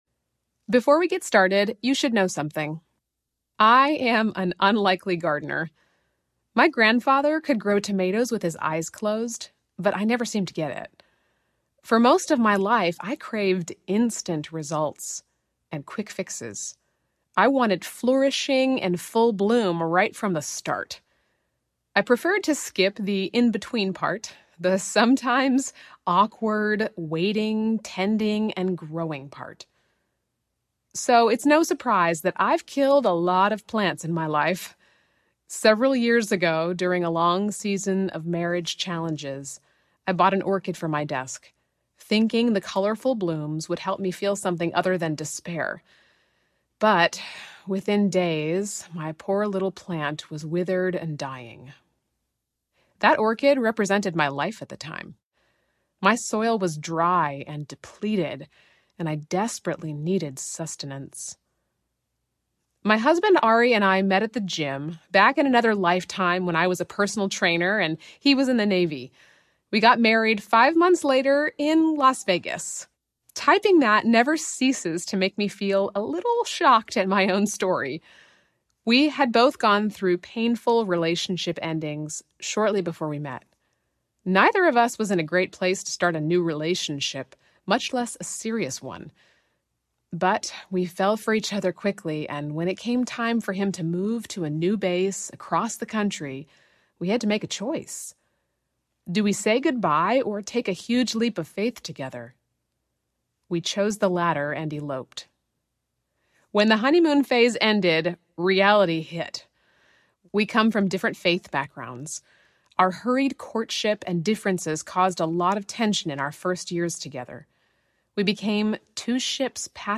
Cultivate Audiobook